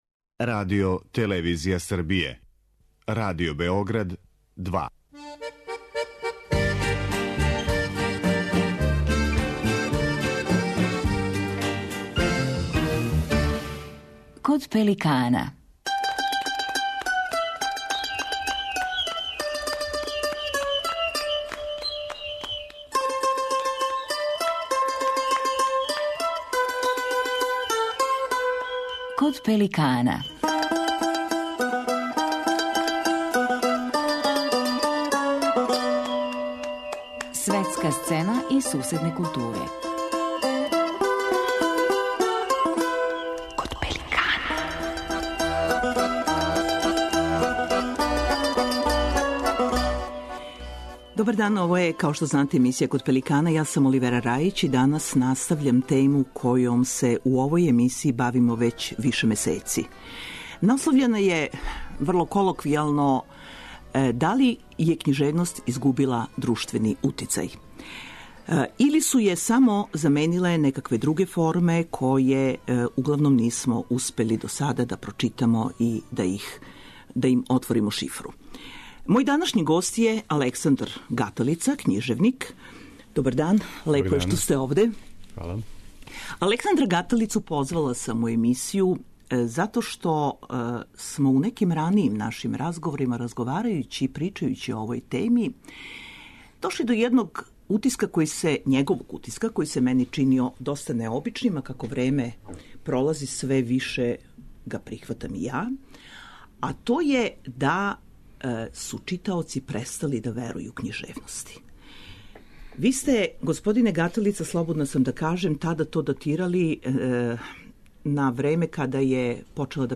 Овакво уверење често износи књижевник Александар Гаталица, гост данашњег издања емисије Код пеликана.